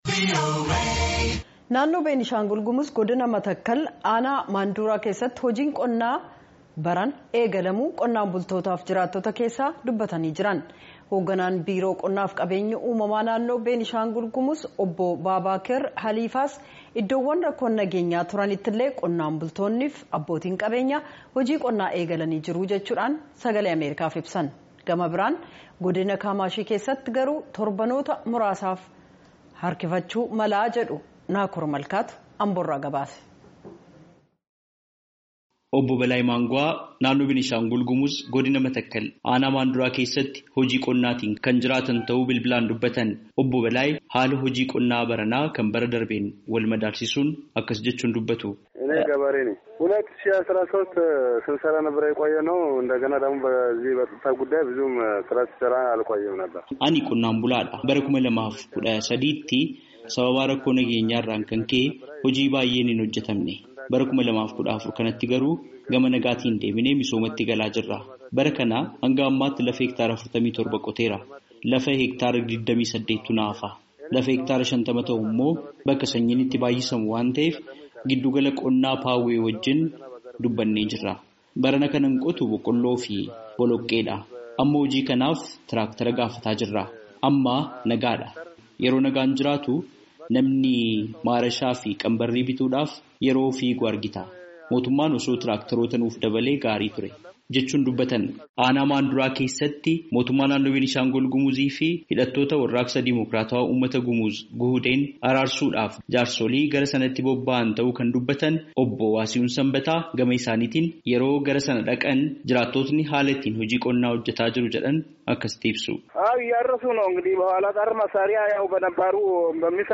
Naannoo Benishaangul Gumuz Godina Matakkal, anaa Maanduraa keessatti, hojiin qonna baranaa eegalamuu qonnaan bultootaa fi jiraattota keessaa dubbataa jiran.